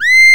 VOICE C6 F.wav